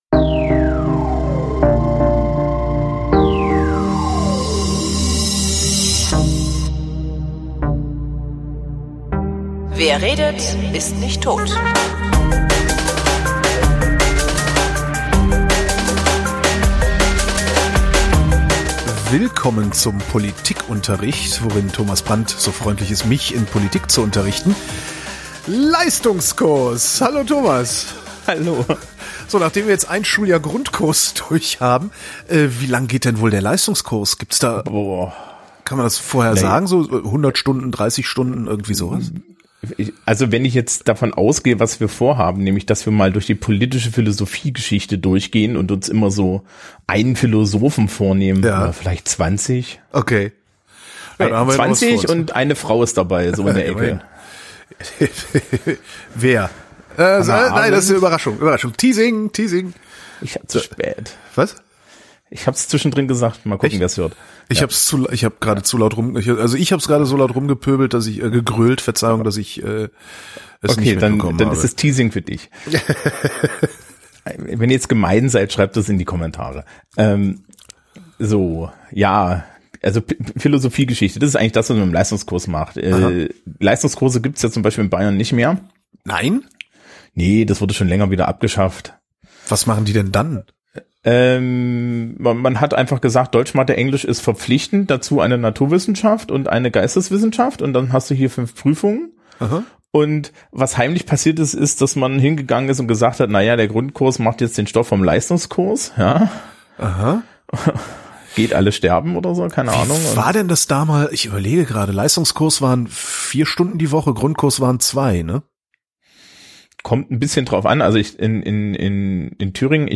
In der ersten Veröffentlichung war eine Tonspur teilweise weg. Der Fehler sollte jetzt behoben sein.